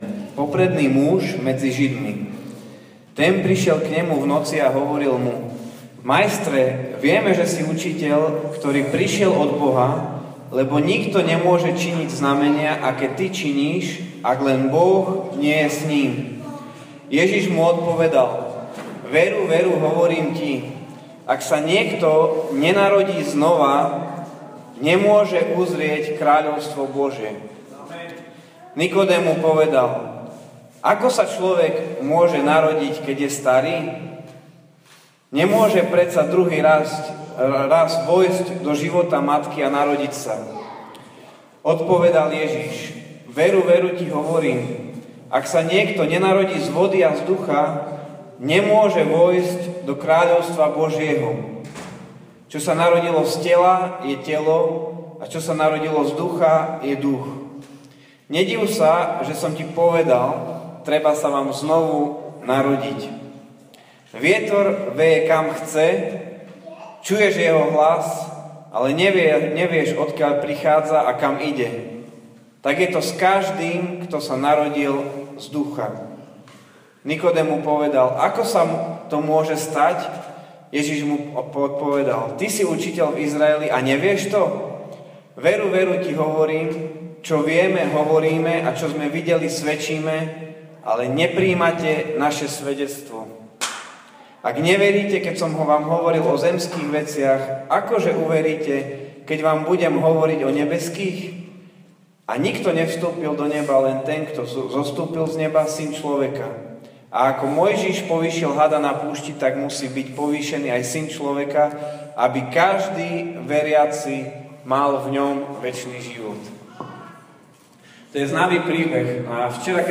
Nedeľné slovo z CHATovačky Otcovho domu v Čičmanoch. Ospravedlňujeme sa za zníženú kvalitu nahrávky.